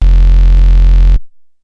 A#_07_Bass_01_SP.wav